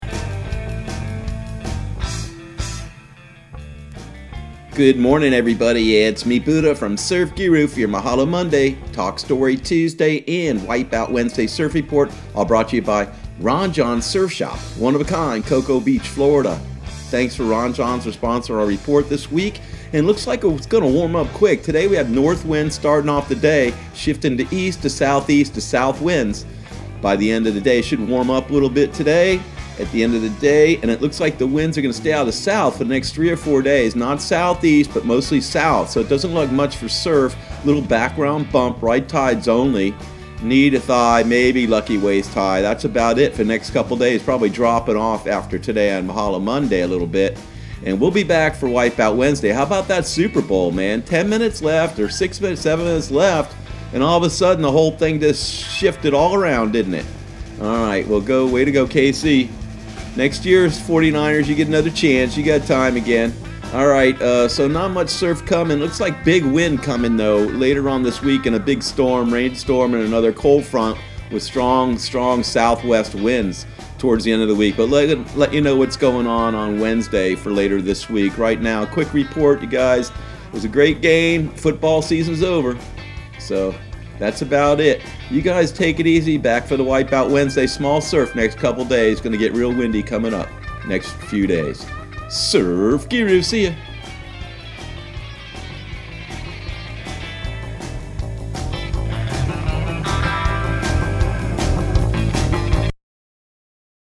Surf Guru Surf Report and Forecast 02/03/2020 Audio surf report and surf forecast on February 03 for Central Florida and the Southeast.